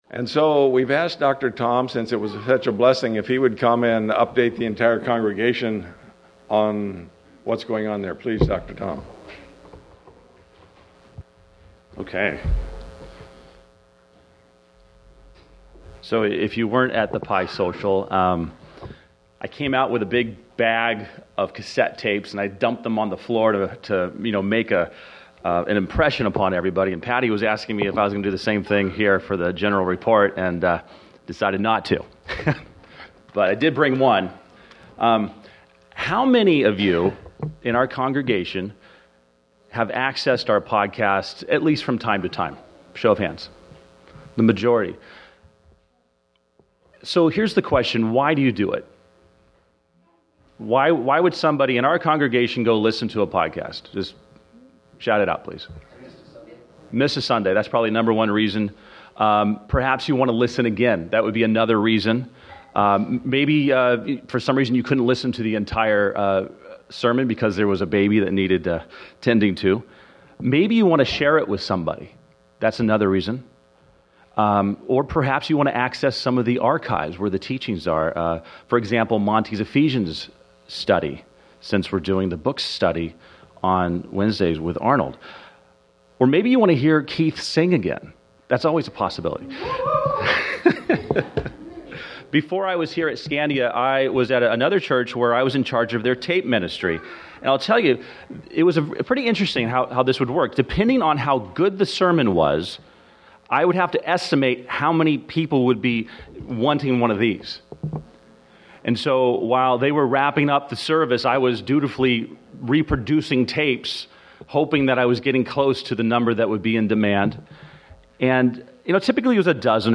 At the Annual Business Meeting, we bring the congregation up to speed on how the podcast has been doing, what’s new, and what’s ahead.